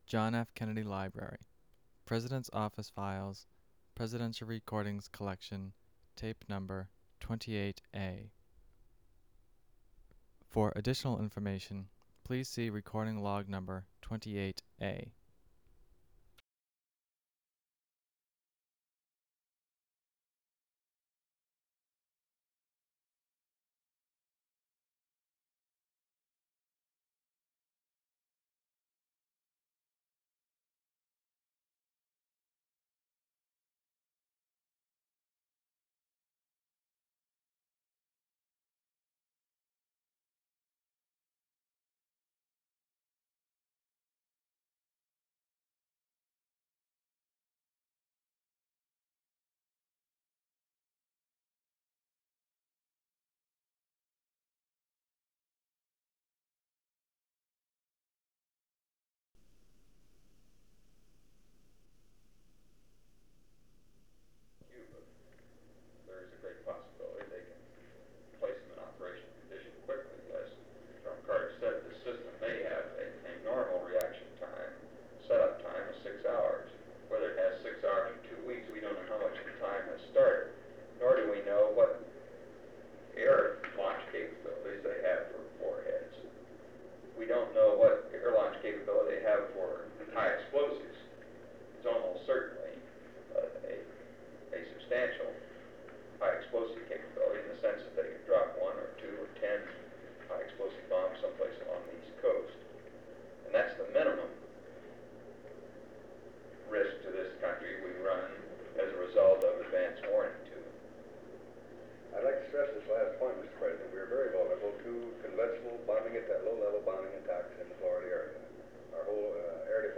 Secret White House Tapes | John F. Kennedy Presidency Meeting on the Cuban Missile Crisis (cont.)